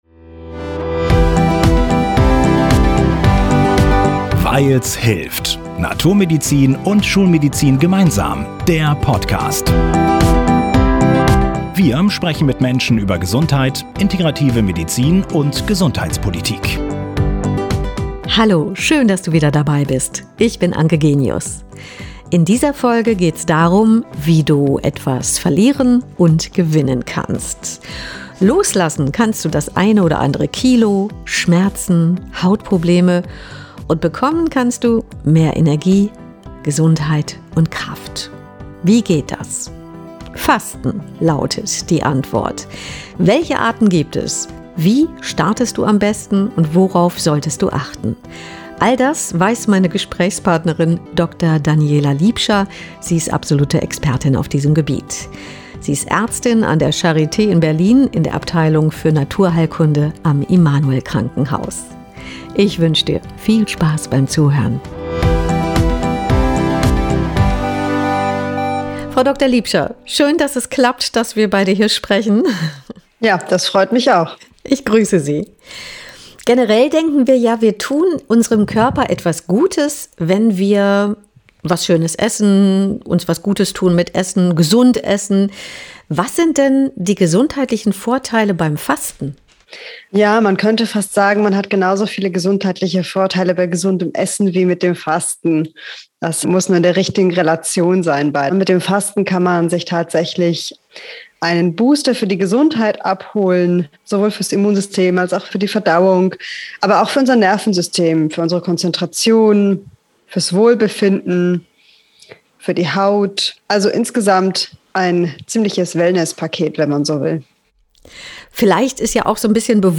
Mehr zu unserer Interviewpartnerin und weitere Informationen